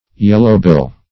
yellowbill - definition of yellowbill - synonyms, pronunciation, spelling from Free Dictionary Search Result for " yellowbill" : The Collaborative International Dictionary of English v.0.48: Yellowbill \Yel"low*bill`\, n. (Zool.)